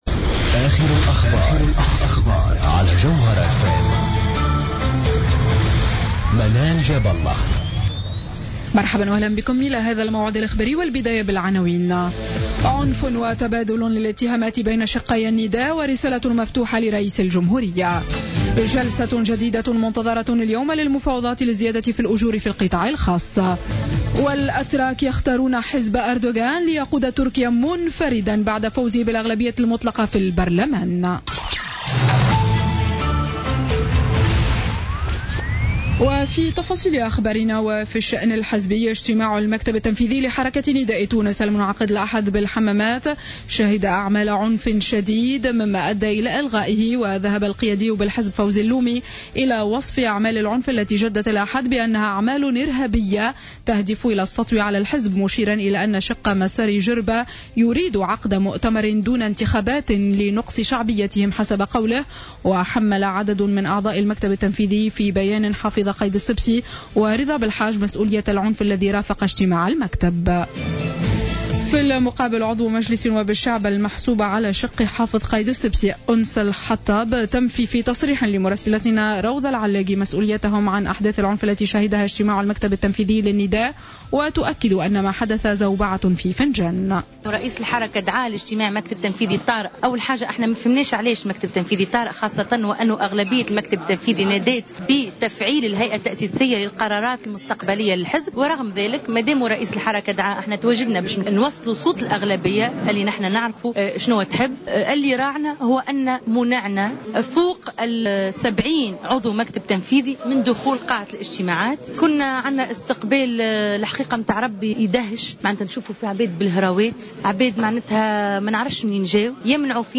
نشرة أخبار منتصف الليل ليوم الإثنين 02 نوفمبر 2015